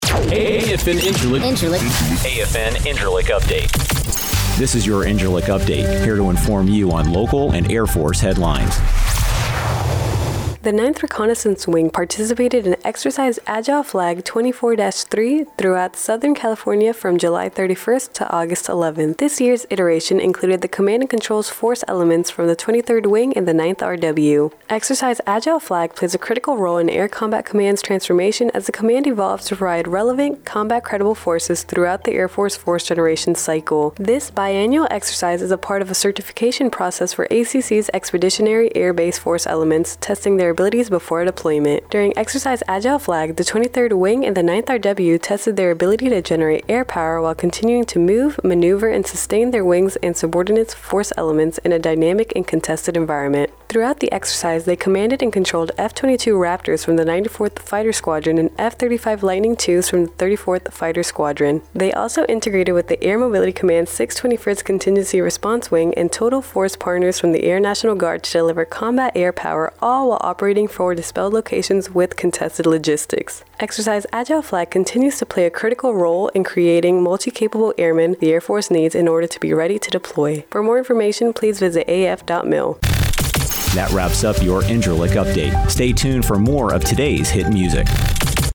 dmaAgile FlagnewscastAFNIncirlik